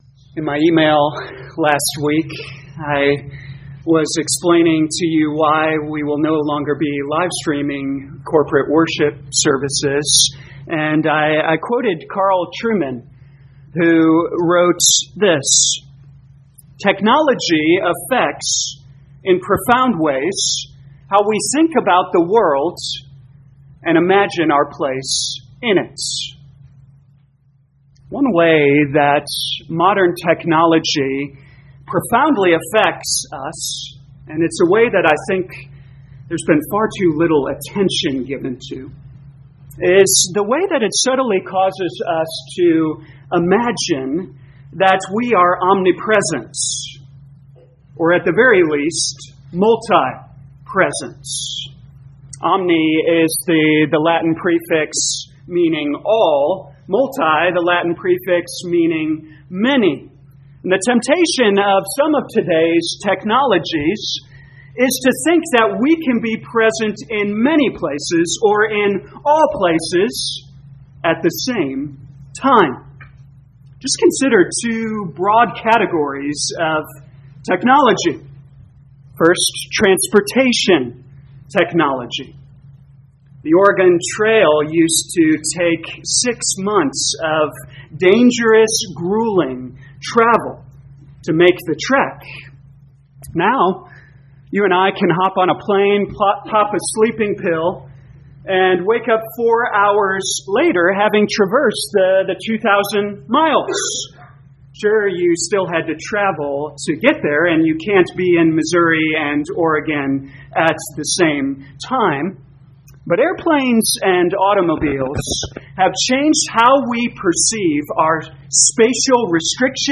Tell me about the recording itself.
2023 Jeremiah Knowing God Morning Service Download